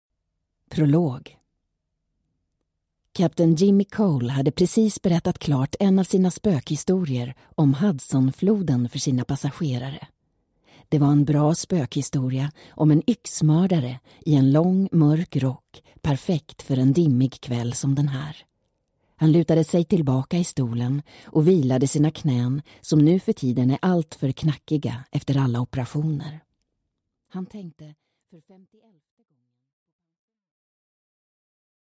Аудиокнига Tagen | Библиотека аудиокниг